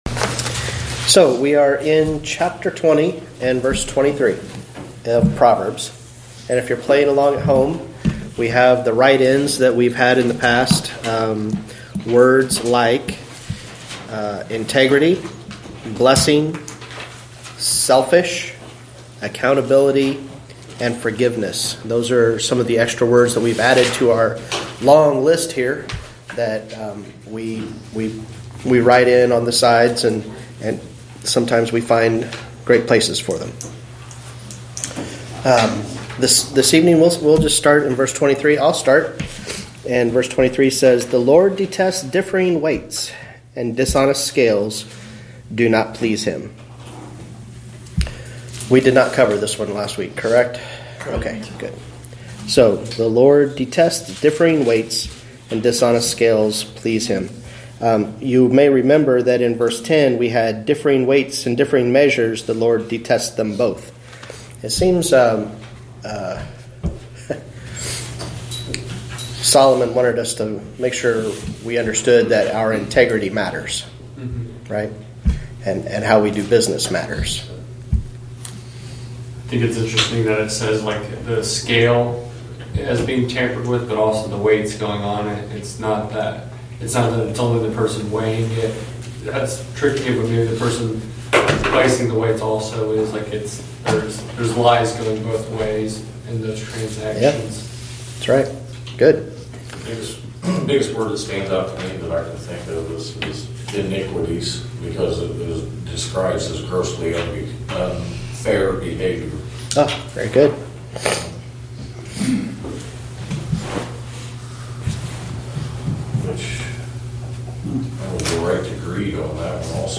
Wednesday Evening Bible Study - Proverbs 20:23-30